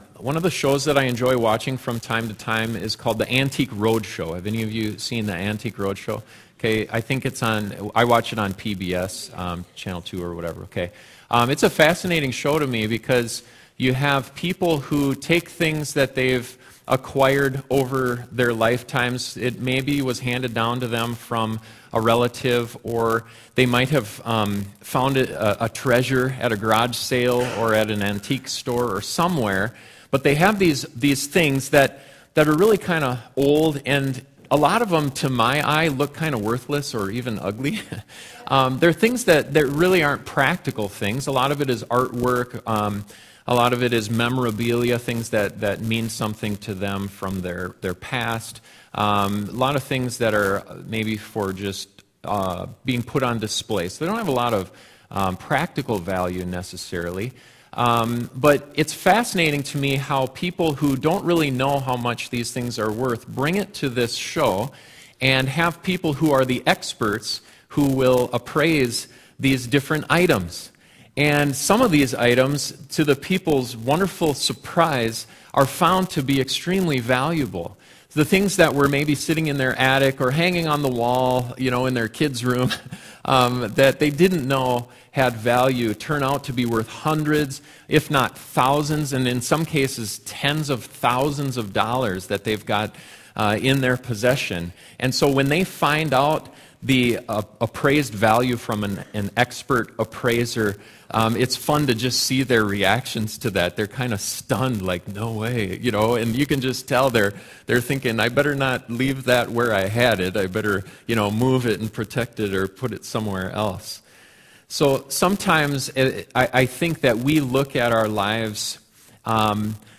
(audio buzz in first 18 minutes of sermon) Sometimes we look at our lives and compare ourselves with the people around us and feel like we’re worthless.